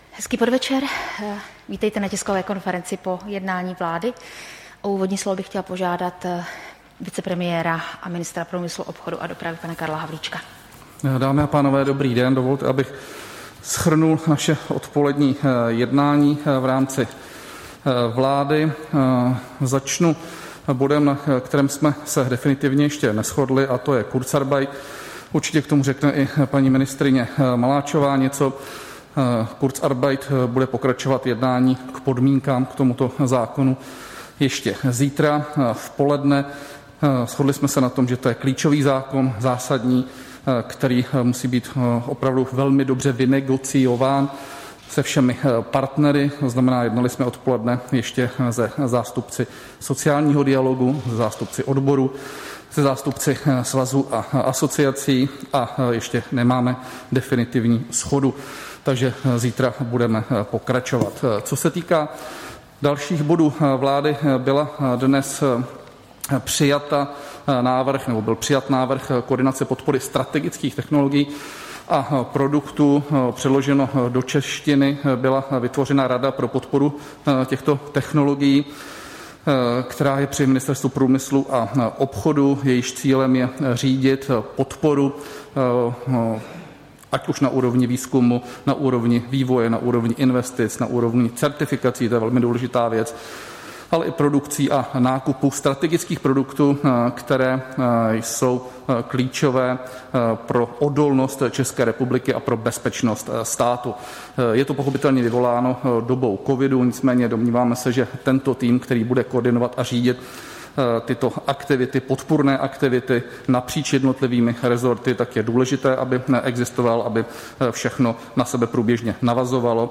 Tisková konference po jednání vlády, 14. září 2020.